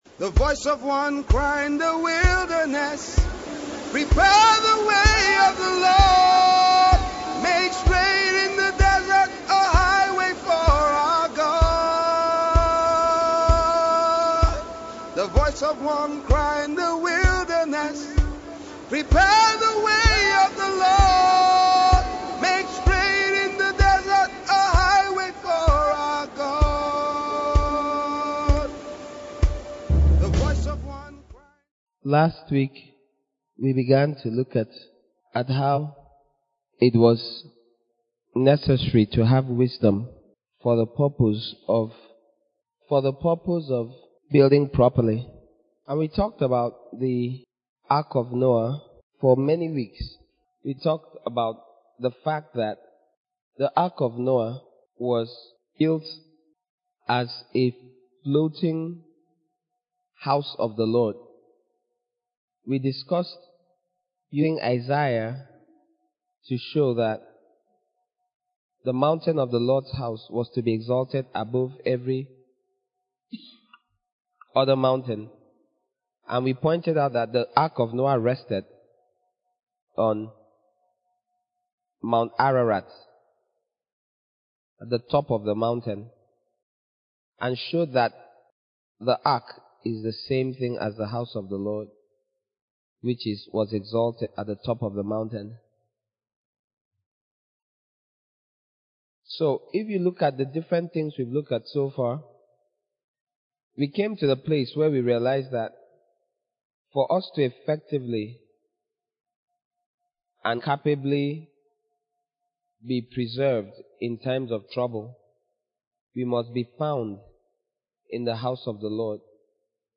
Sermon From Series: "The Wisdom Of God"